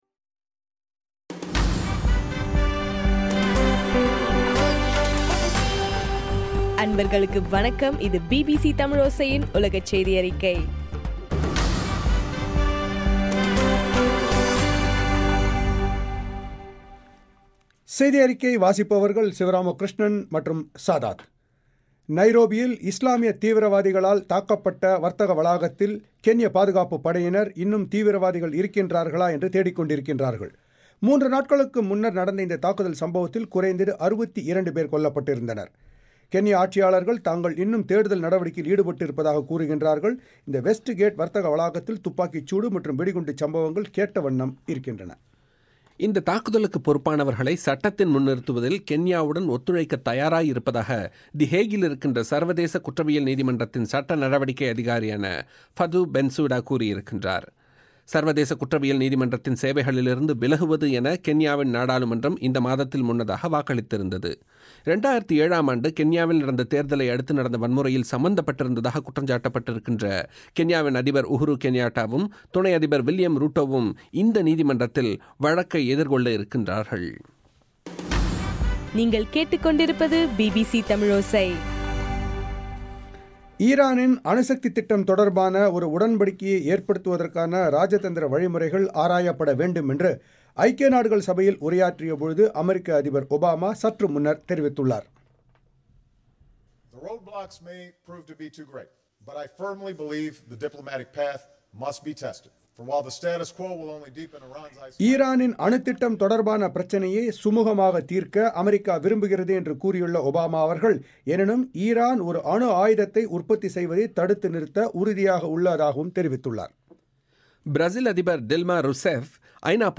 செப்டம்பர் 24 பிபிசி தமிழோசை உலகச் செய்தி அறிக்கை